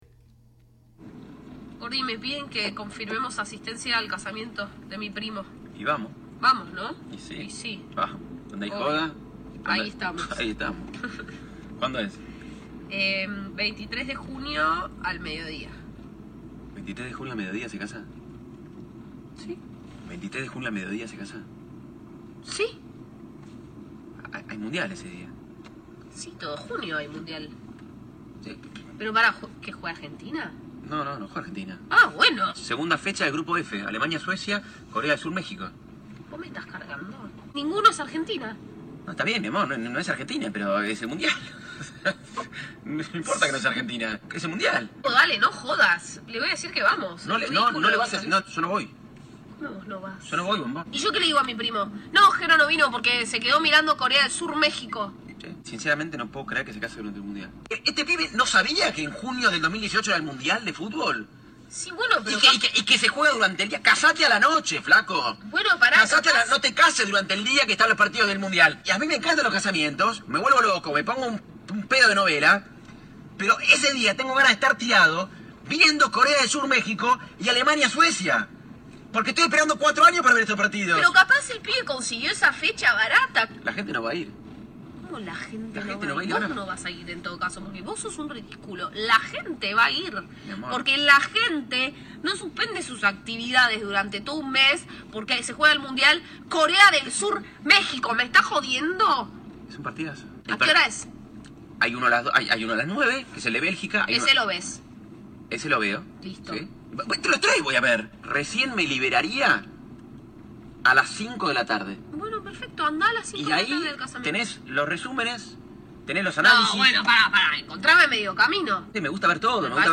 Hoy te acompañamos con buena música y te deseamos un excelente dia.